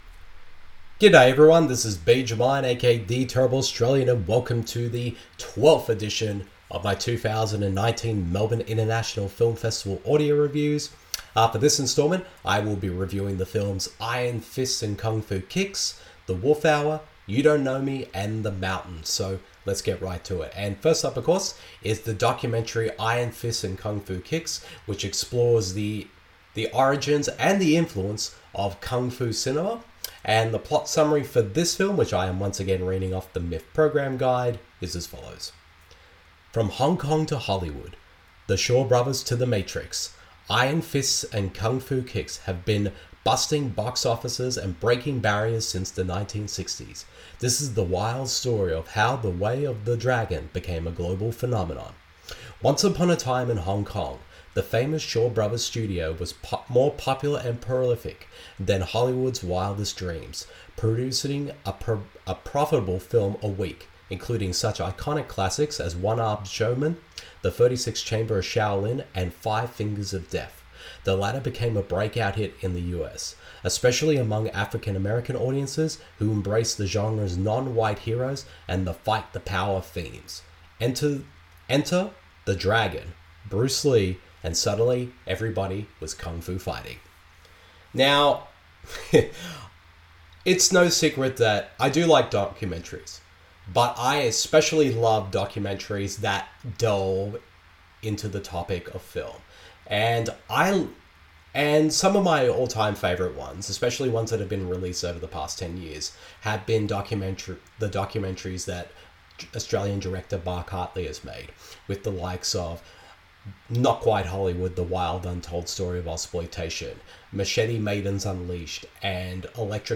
IRON FISTS AND KUNG FU KICKS , THE WOLF HOUR , YOU DON’T NOMI and THE MOUNTAIN Welcome to the 12th edition of my 2019 Melbourne International Film Festival (a.k.a. MIFF) audio reviews. In this one, I share my thoughts on the documentary on Kung Fu cinema IRON FISTS AND KUNG FU KICKS, the Naomi Watts starring psychological drama THE WOLF HOUR, the SHOWGIRLS documentary YOU DON’T NOMI and the quirky drama THE MOUNTAIN.